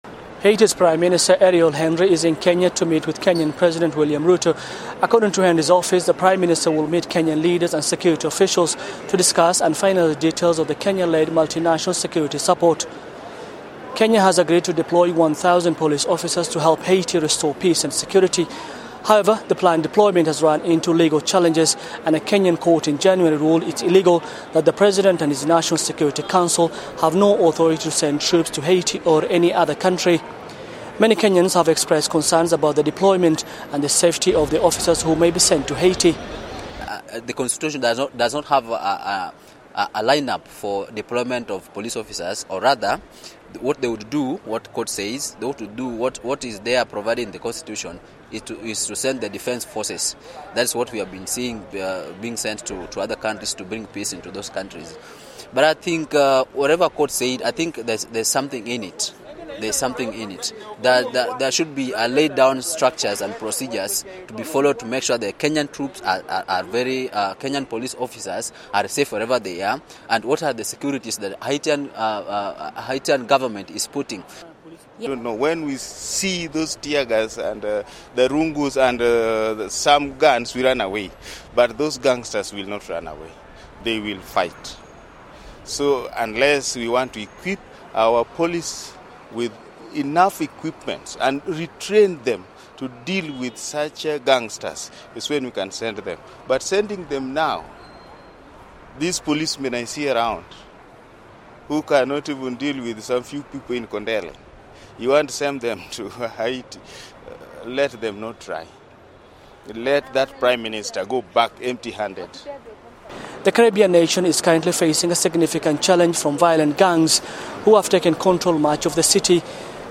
spoke with some Kenyans about the planned mission to Haiti.